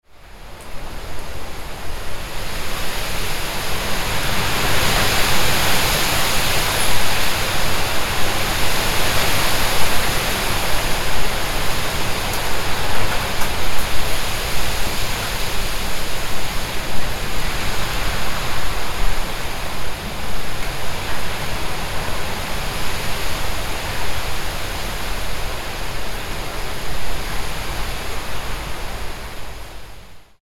Strong Wind Blowing Through Leaves Sound Effect
A strong autumn wind sweeps through the trees, rustling the colorful leaves. Experience the crisp, lively sounds of fall as the wind moves through the forest.
Strong-wind-blowing-through-leaves-sound-effect.mp3